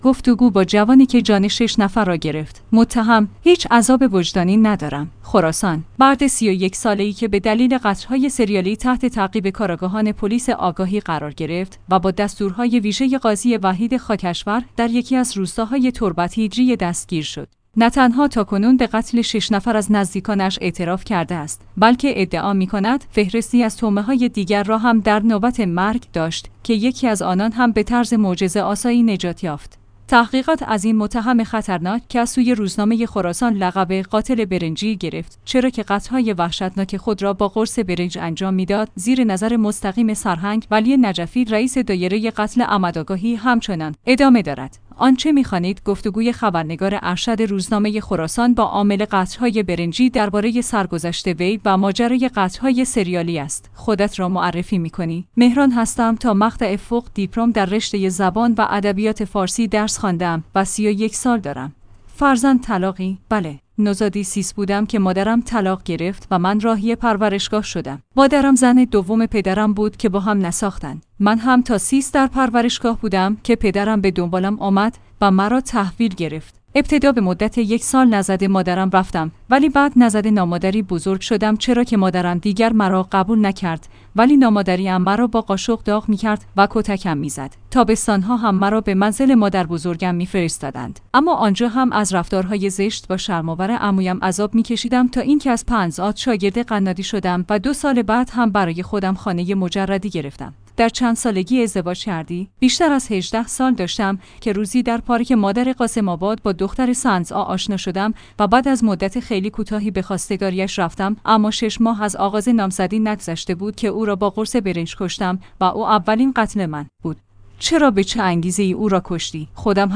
گفتگو با جوانی که جان 6 نفر را گرفت؛ متهم: هیچ عذاب وجدانی ندارم